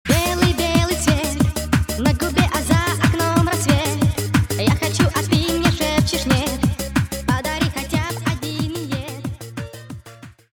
• Качество: 320, Stereo
быстрые
смешной голос
цикличные
Быстрый рингтон с забавным голосом